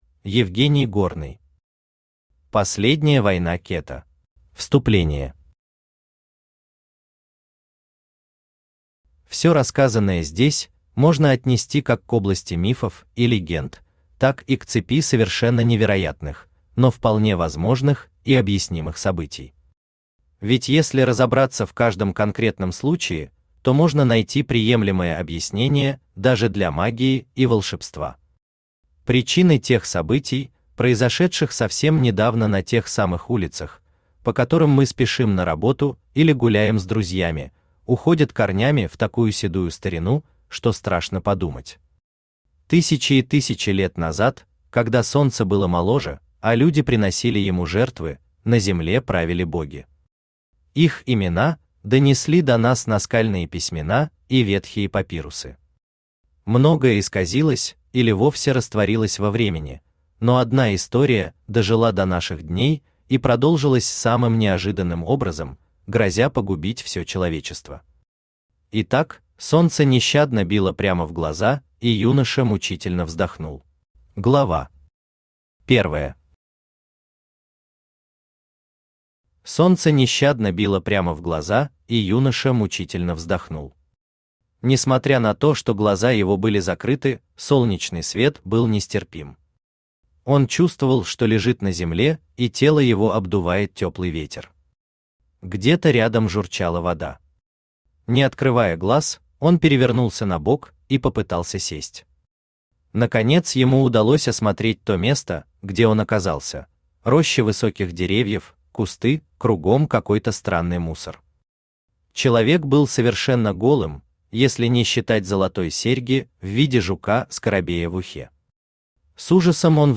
Aудиокнига Последняя война Cета Автор Евгений Горный Читает аудиокнигу Авточтец ЛитРес. Прослушать и бесплатно скачать фрагмент аудиокниги